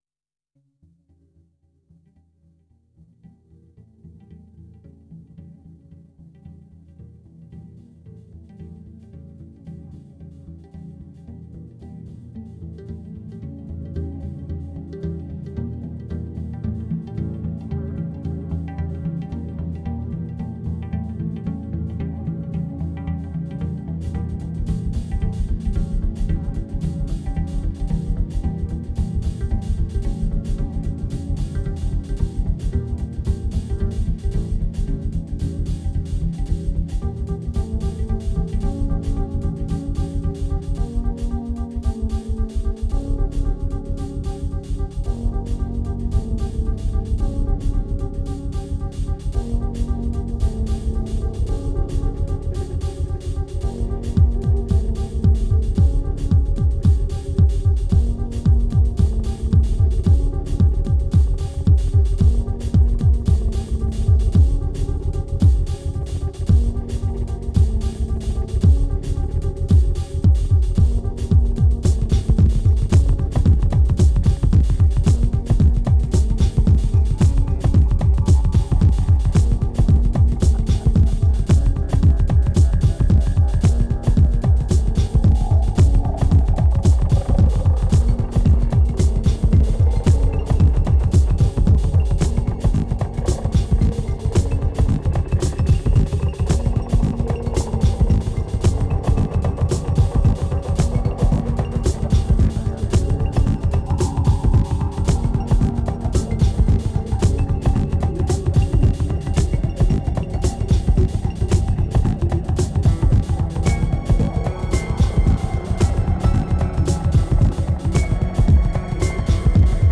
2369📈 - 13%🤔 - 112BPM🔊 - 2010-11-11📅 - -224🌟